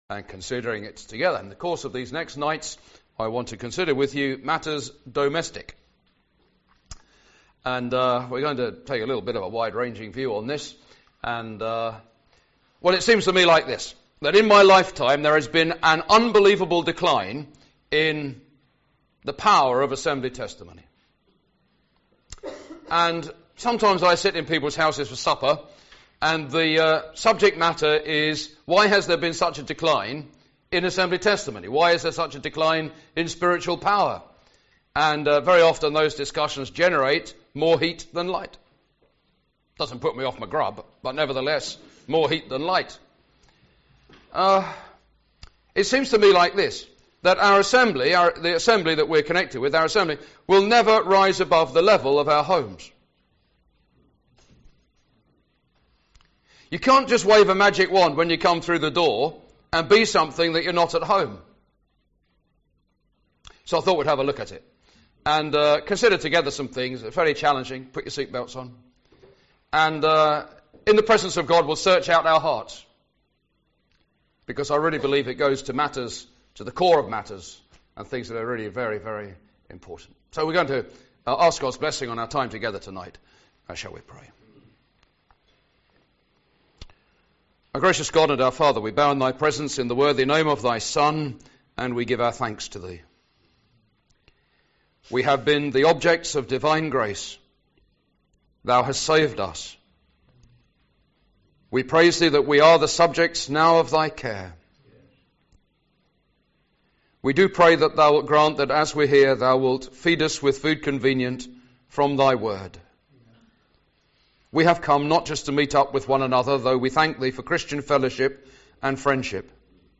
19-24 Service Type: Ministry